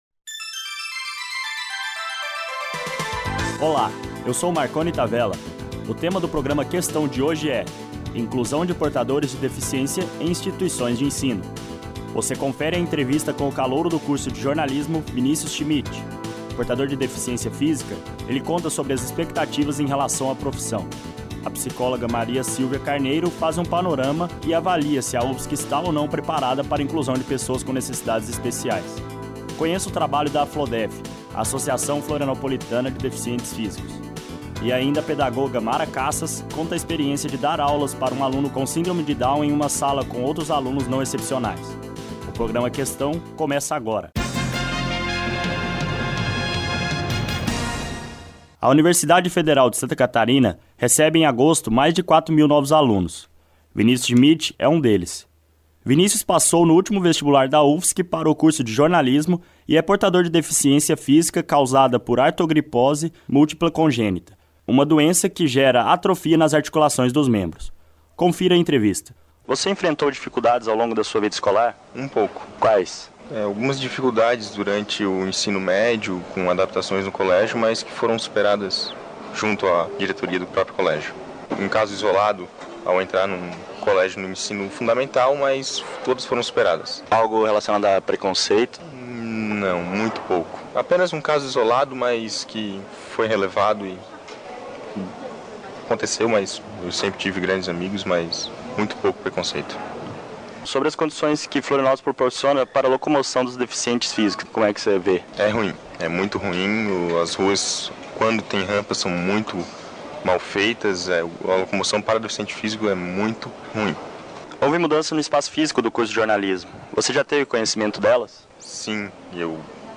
Os portadores de deficiência e suas dificuldades são temas deste programa de entrevistas.